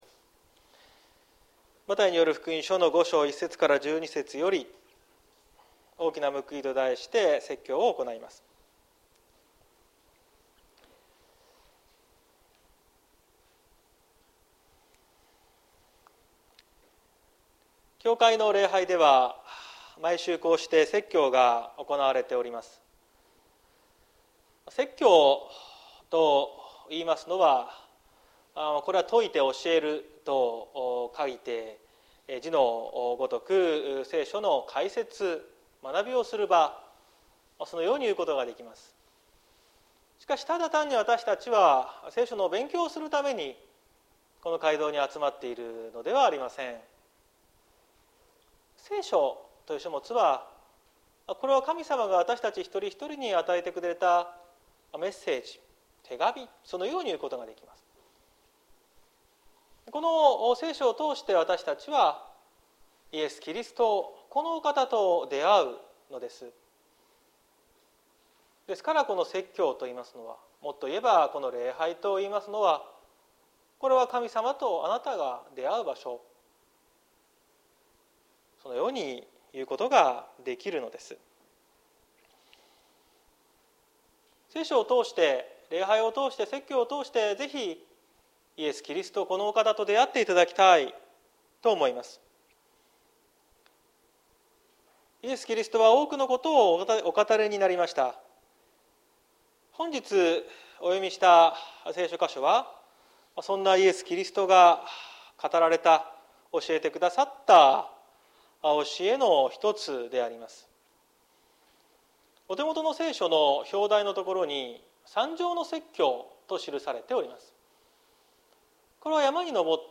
2022年09月04日朝の礼拝「大きな報い」綱島教会
説教アーカイブ。